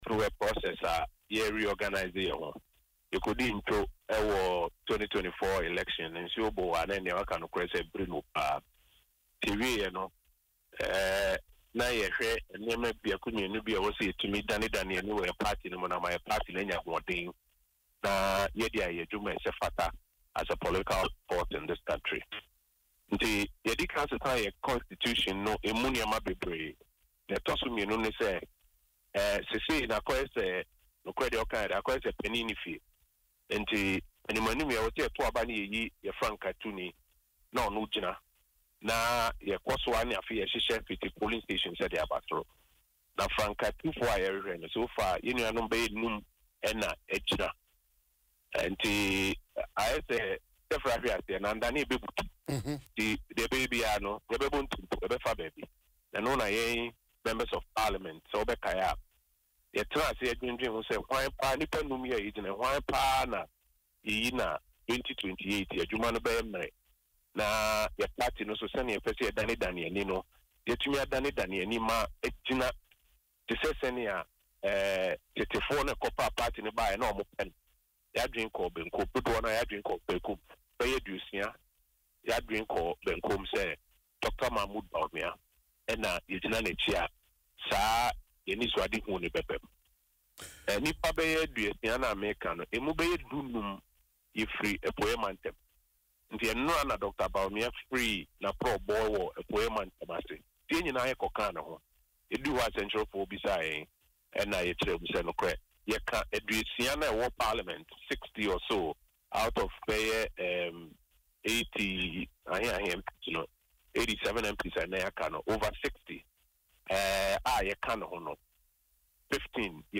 Speaking on Dwaso Nsem on Adom FM, the former Information Minister said the New Patriotic Party (NPP) is in a phase of rebuilding following its defeat in the 2024 general elections.